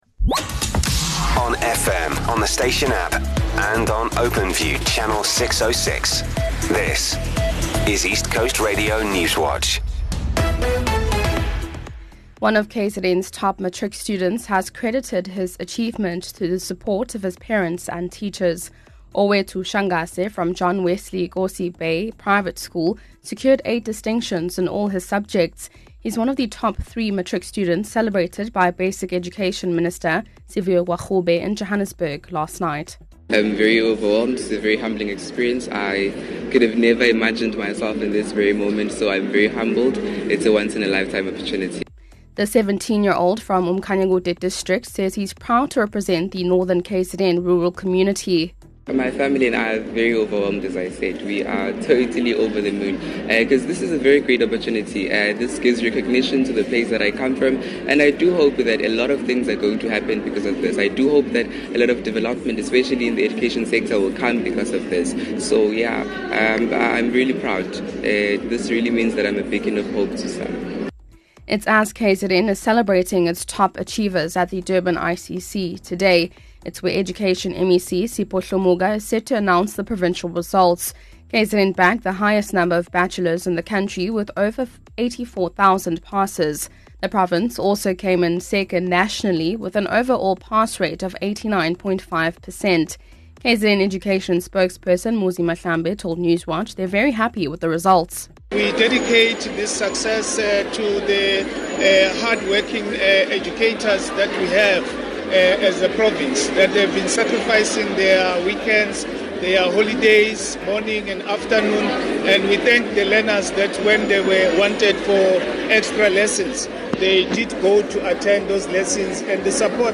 Here’s your latest ECR Newswatch bulletin from the team at East Coast Radio.